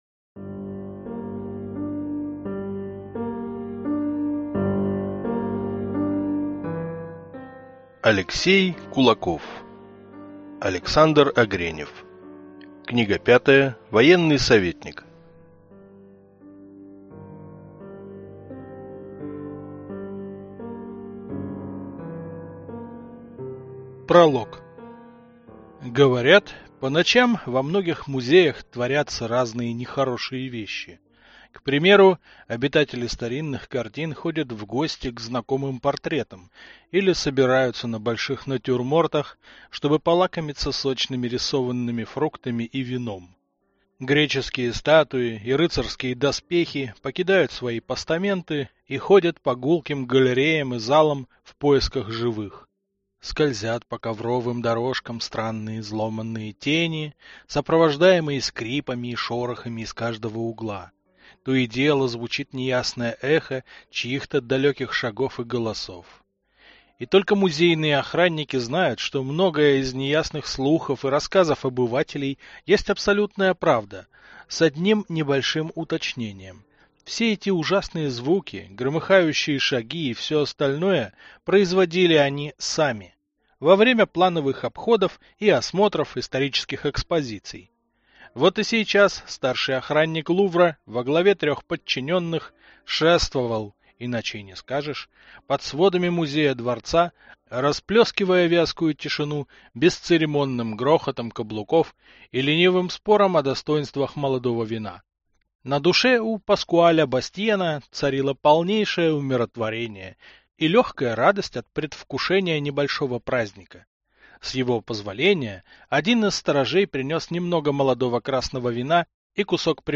Аудиокнига Военный советникъ | Библиотека аудиокниг